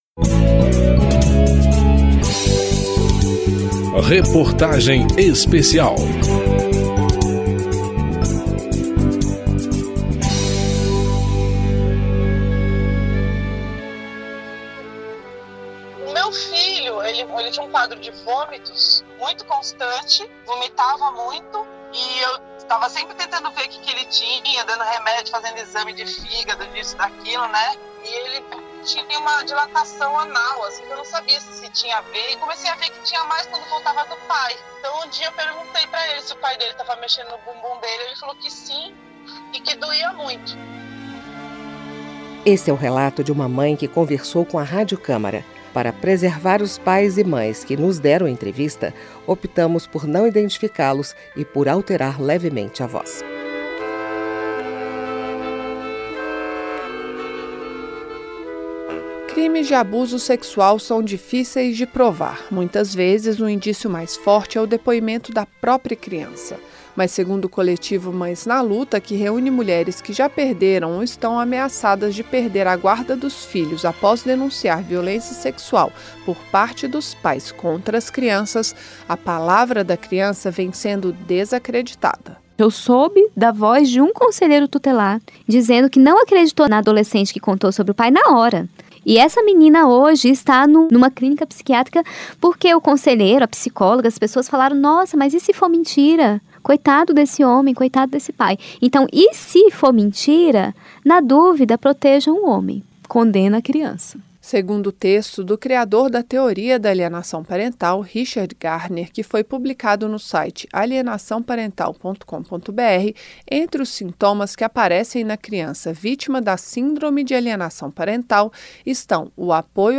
Reportagem Especial
Para preservar os pais e mães que deram entrevista, optamos por não identificá-los e por alterar levemente a voz.